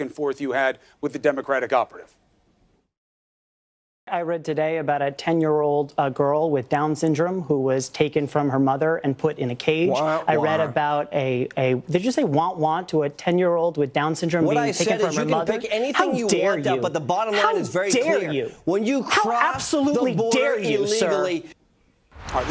Womp Womp News Reporter Meme Sound Effect Free Download